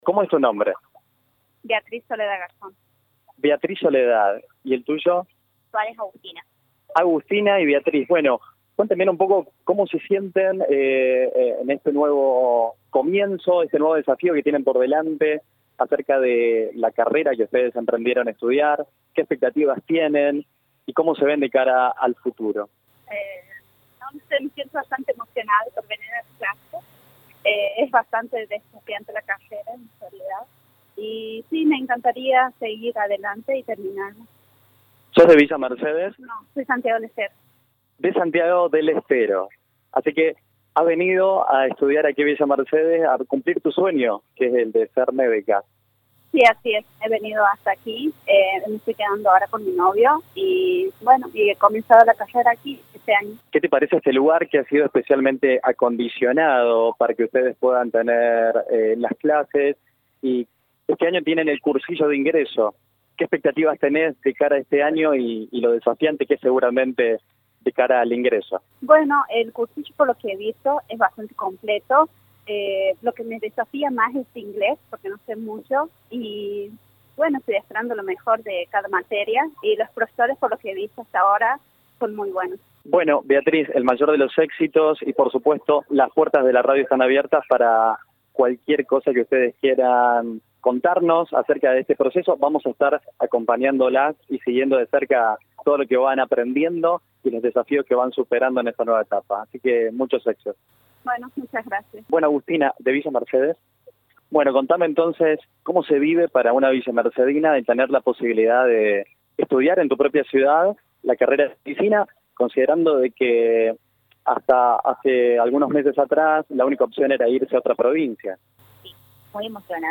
ALUMNAS-INGRESANTES-A-MEDICINA.mp3